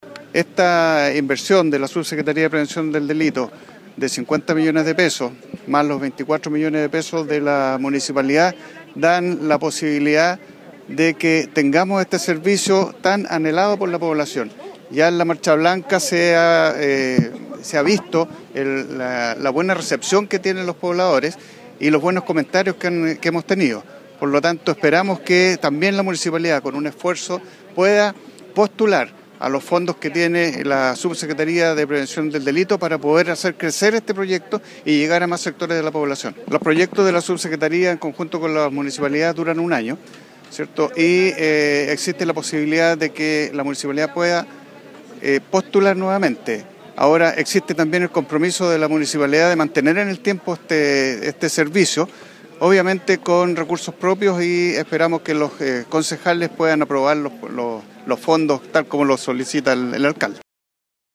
La mañana de este martes se llevó a cabo la ceremonia de inauguración del proyecto Patrullaje Preventivo “MAS SEGURIDAD”.
El alcalde de la comuna de Copiapó, explicó la forma, horarios y lugar en que estarán presentes los patrullajes preventivos en la zona centro de la ciudad: